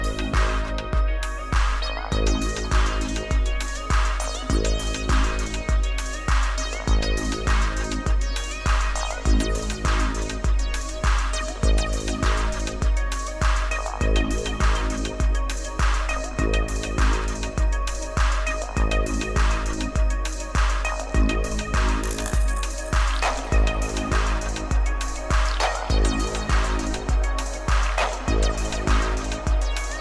a música aqui é alienígena e minimalista